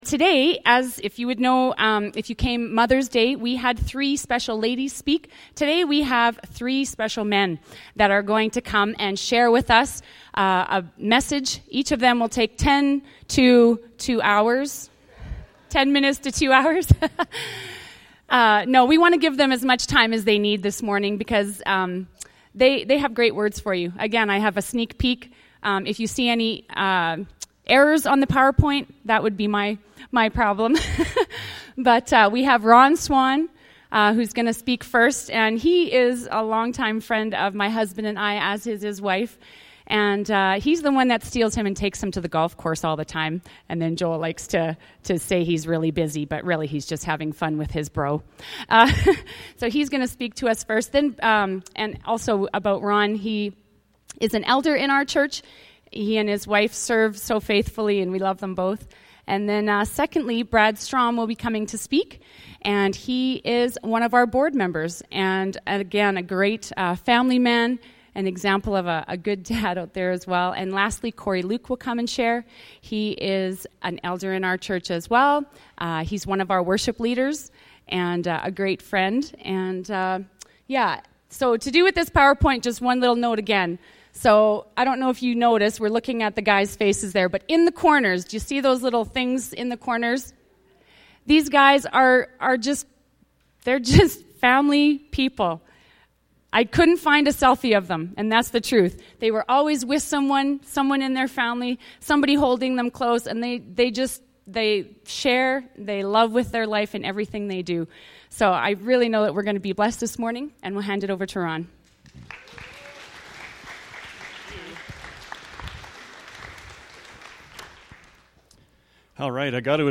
Stand Alone Message Loving Your Enemies May 26